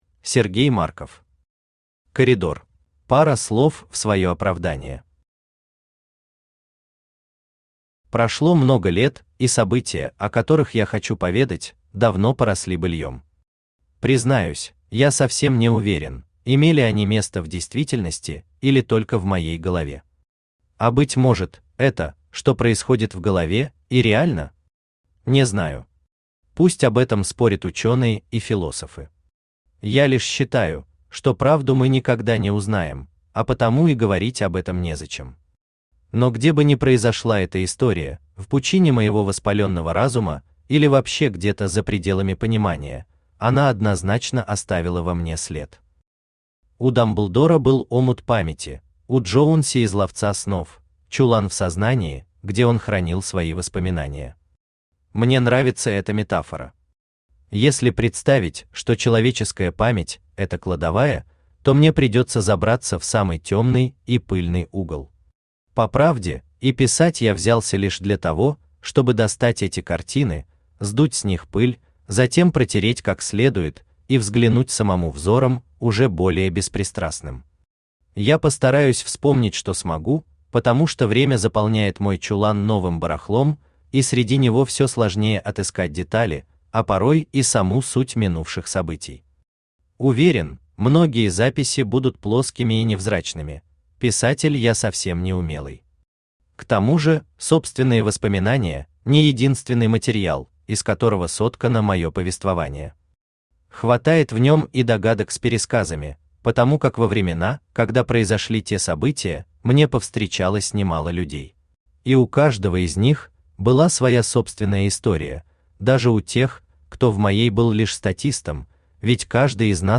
Aудиокнига Коридор Автор Сергей Марков Читает аудиокнигу Авточтец ЛитРес.